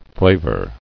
[fla·vor]